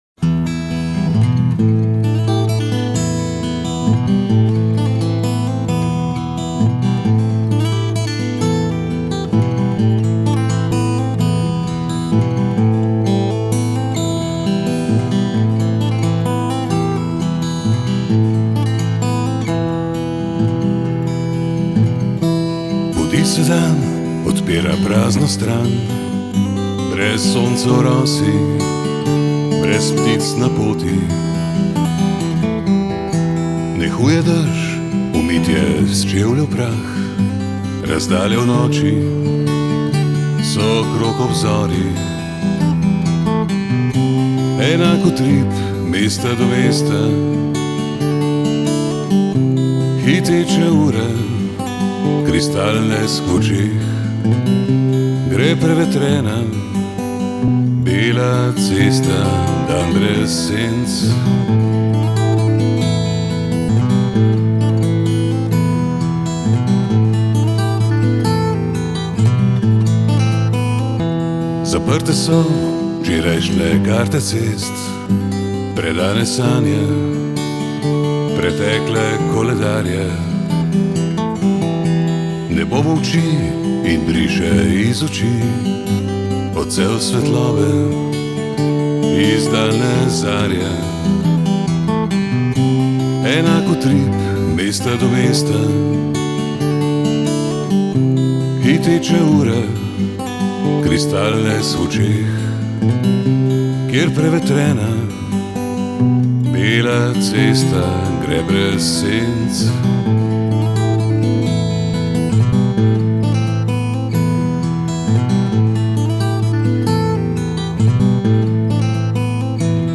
ustvarja intimno vzdušje z glasom in kitaro